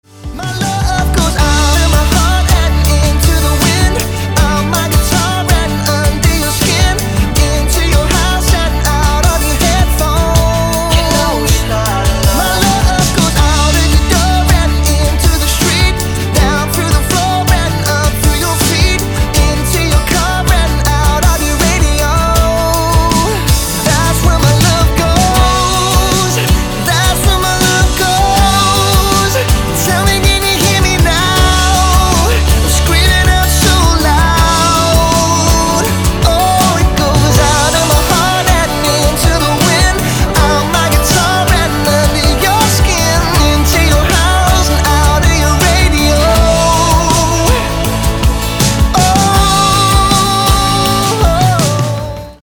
• Качество: 256, Stereo
гитара
мужской вокал
dance
Pop Rock
рок